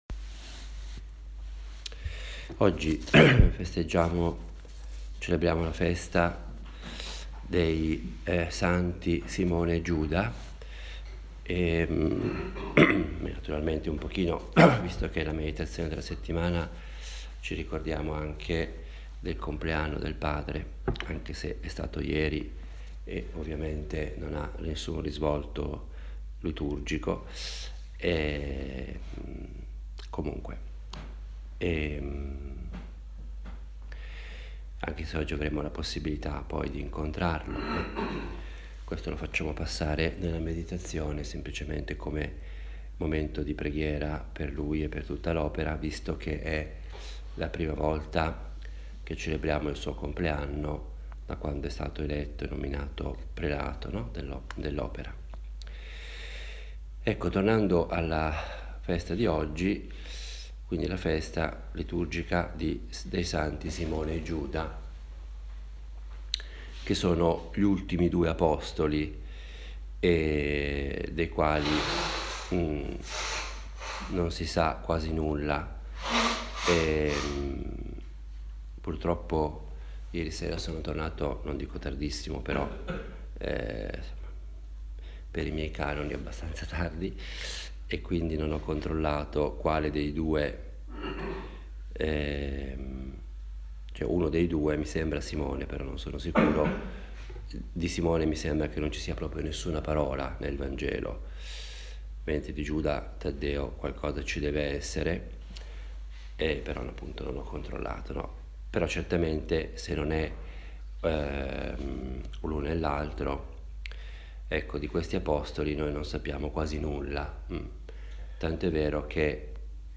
Meditazione predicata il 28 ottobre nella festa dei santi Simone e Giuda
Ha il carattere piano, proprio di una conversazione familiare e io la intendo come il mio dialogo personale – fatto ad alta voce – con Dio, la Madonna, ecc. In genere do un titolo alle meditazione e cerco di fare molto riferimento alla scrittura, in particolare al vangelo. Le meditazioni che si trovano sul blog sono semplici registrazioni – senza nessuna pretesa particolare – di quelle che faccio abitualmente.